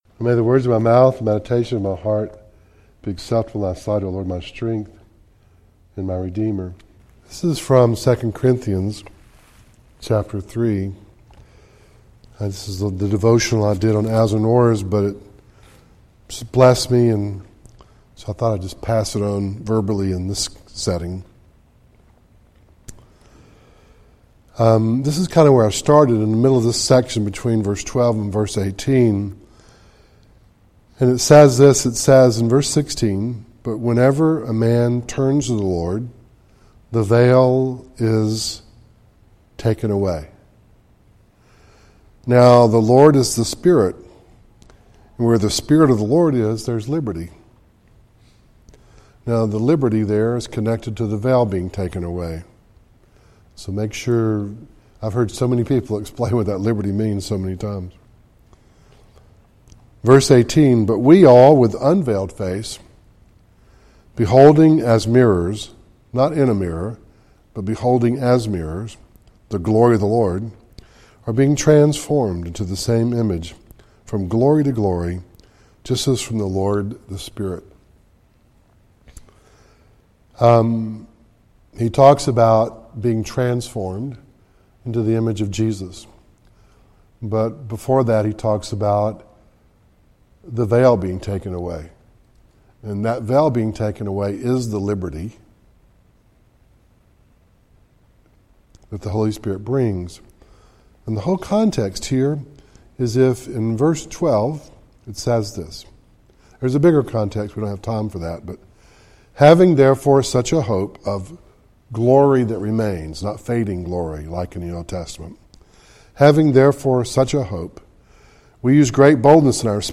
2 Corinthians 3:12-18 Service Type: Devotional